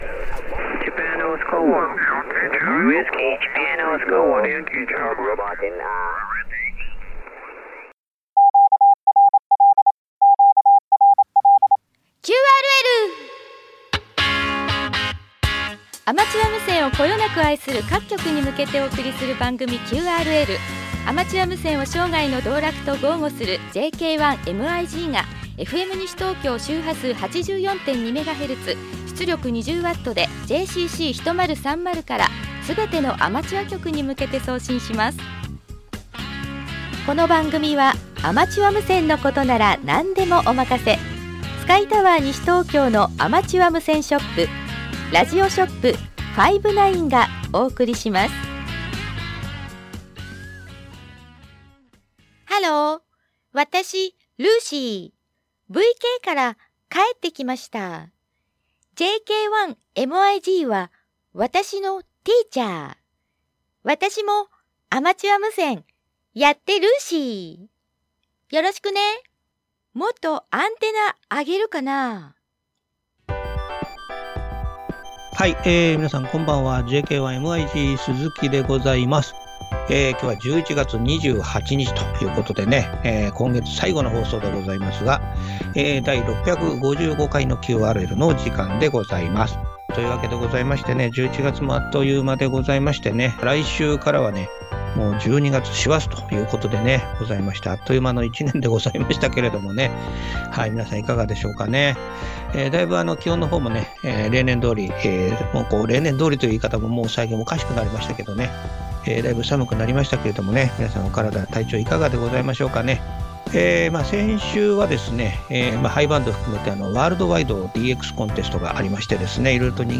コミュニティ放送 ＦＭ西東京(84.2MHz)で毎週木曜日 ２３：３０から２４：００まで放送している アマチュア無線に特化したマニア向け情報番組ＱＲＬ